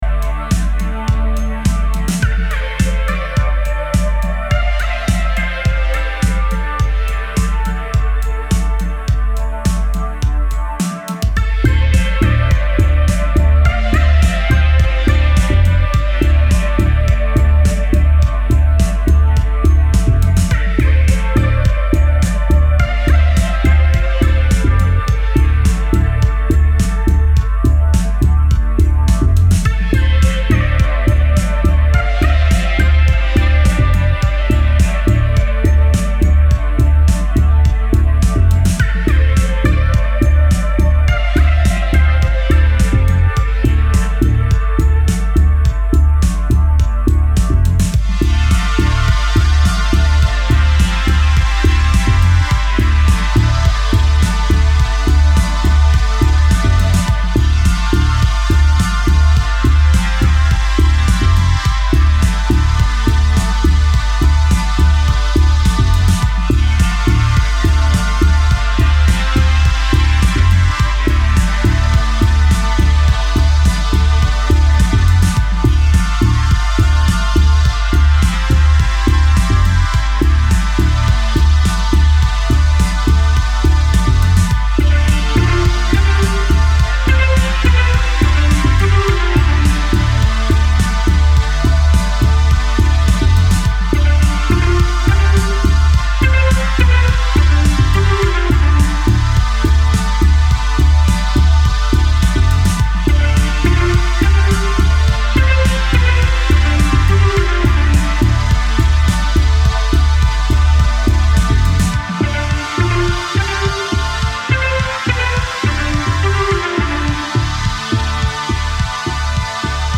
コールドウェイヴ/EBM/コズミック/ニュービート感覚の105BPMミステリアスストンパー。